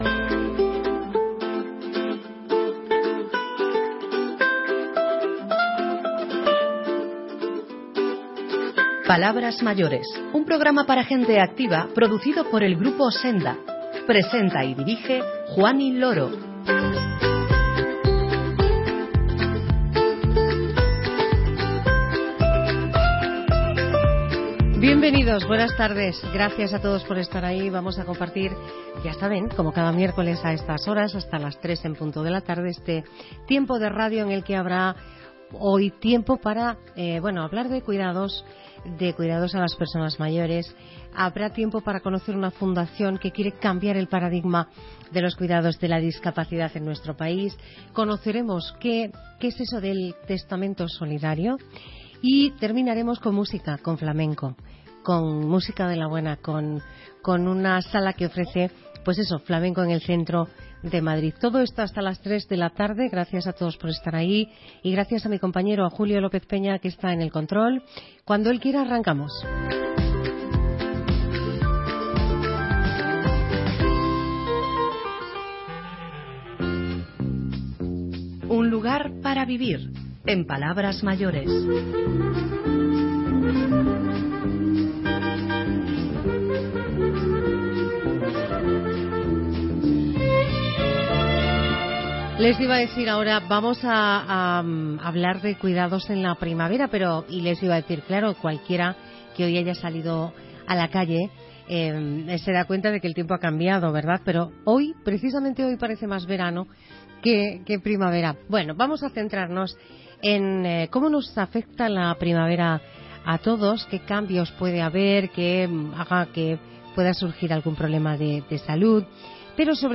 Cuidados, testamento solidario y flamenco, además de poesía y la Fundación 360 en el programa de radio de hoy de Palabras Mayores